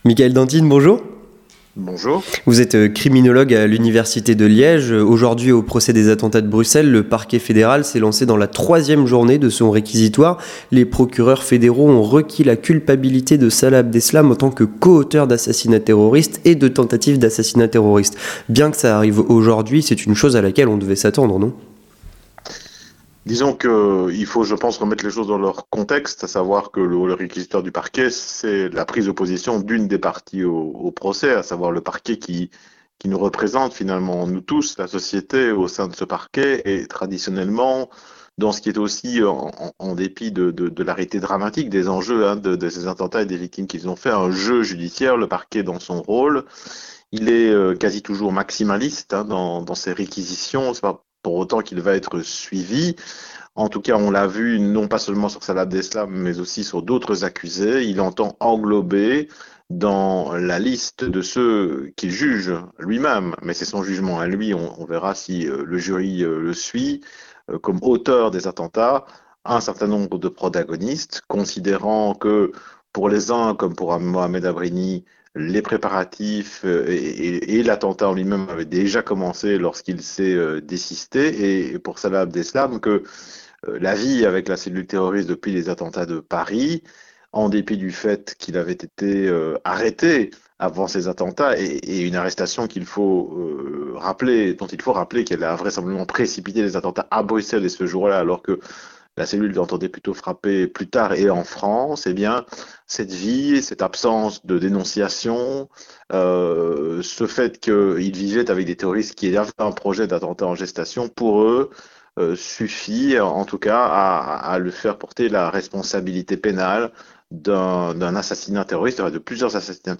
Entretien du 18h - Le procès des attentats de Bruxelles